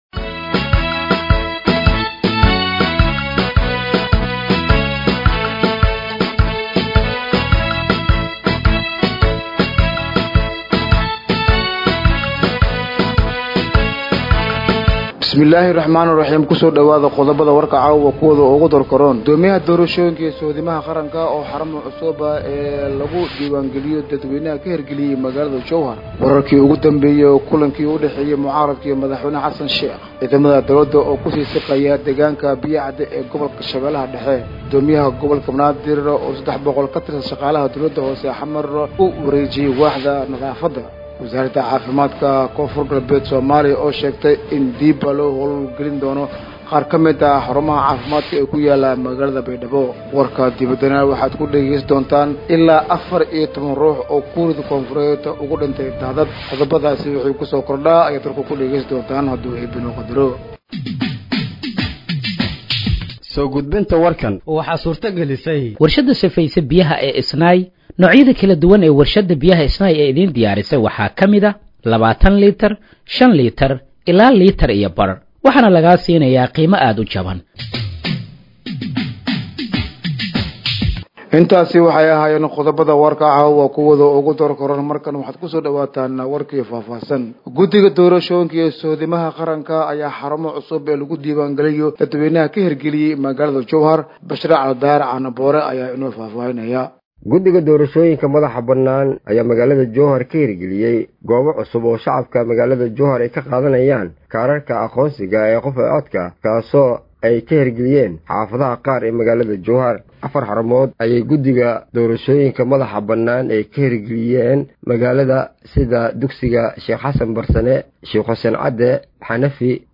Dhageeyso Warka Habeenimo ee Radiojowhar 20/07/2025